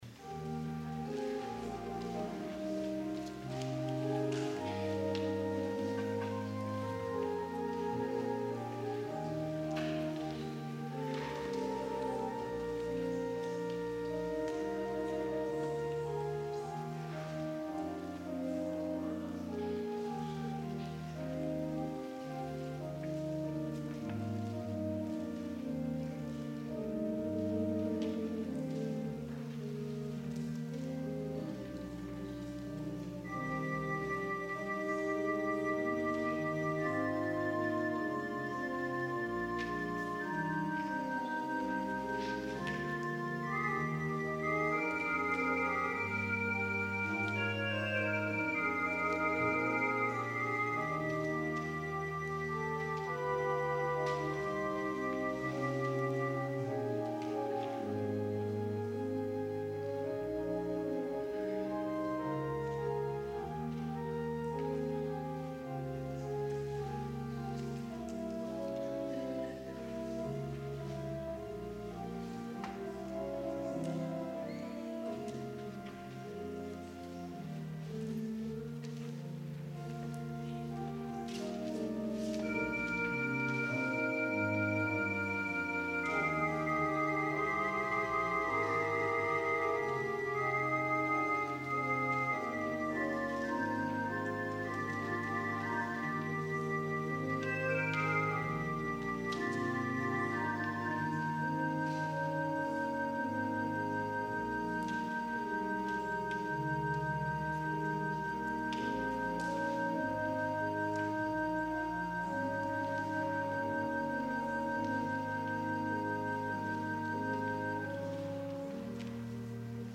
Selection: Chorale Prelude on “Douglass” (McNeil Robinson, 1943-2015);“When in Our Music God Is Glorified”
organ